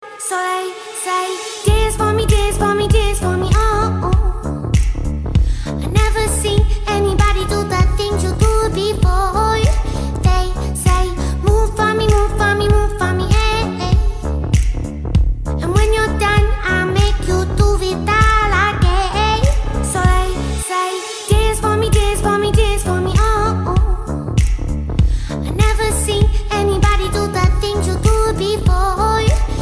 • Качество: 128, Stereo
поп
красивый женский голос
Прикольно звучит женский голос с приятным битом.